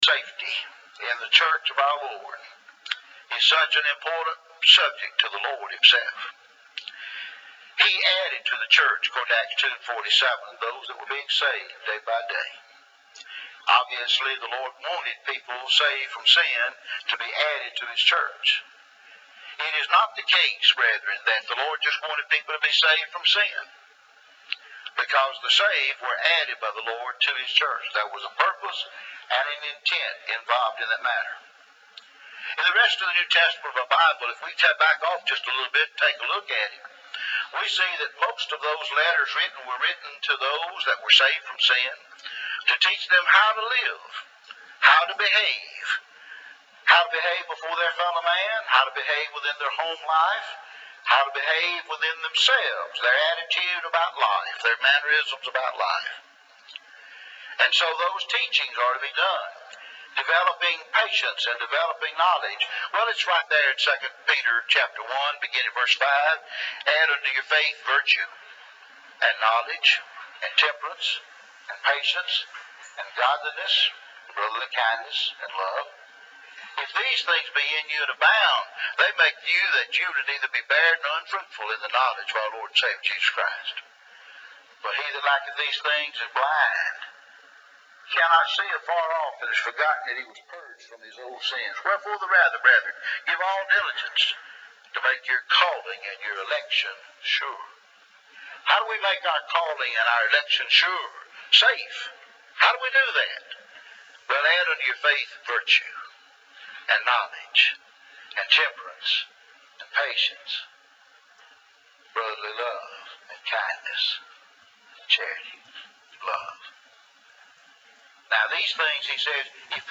Safety in the Church Bible Class